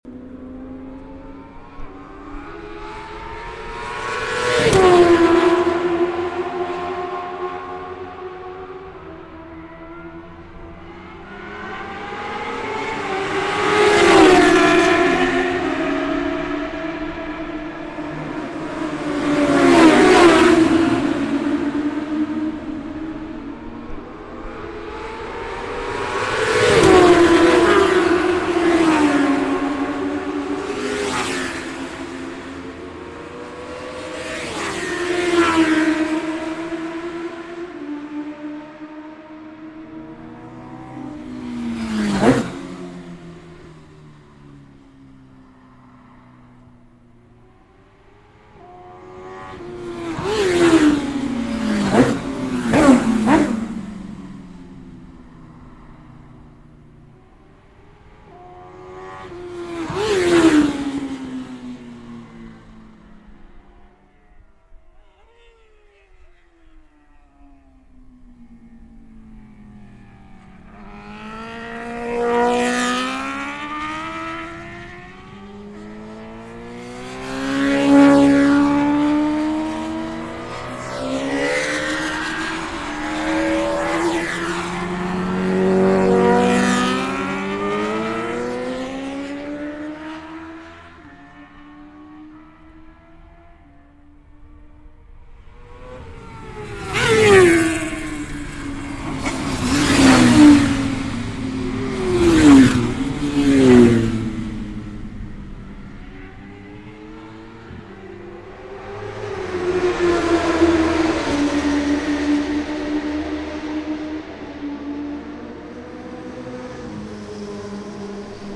oreilles avec de délicieux chants de moteurs et d'échappements qui nous font si souvent
rupteur.mp3